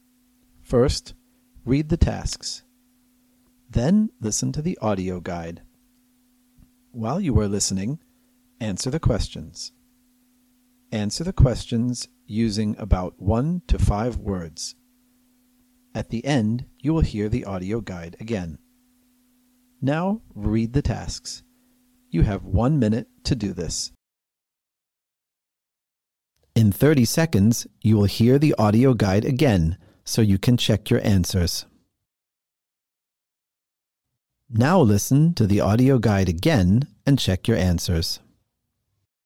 probeaufnahme_zp10_anmoderationen.mp3